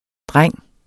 Udtale [ ˈdʁaŋˀ ]